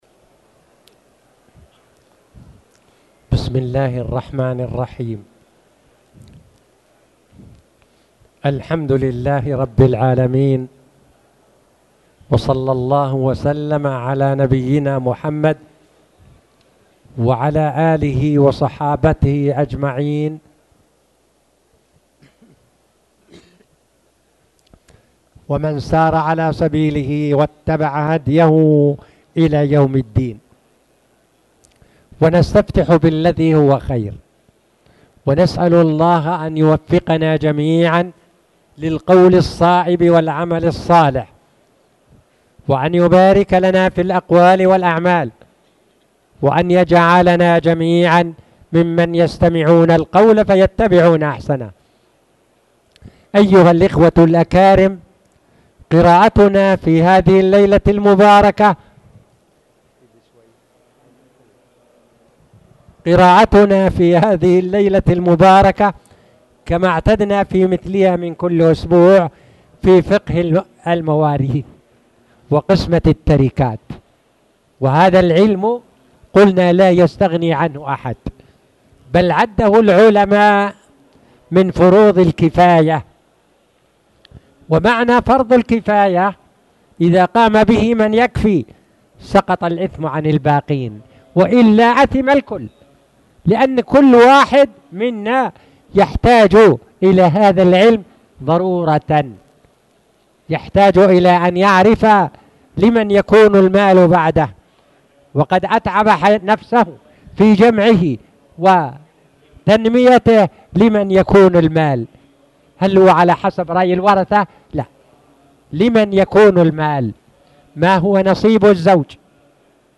تاريخ النشر ٢٧ ربيع الأول ١٤٣٨ هـ المكان: المسجد الحرام الشيخ